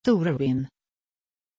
Pronunciation of Torwin
pronunciation-torwin-sv.mp3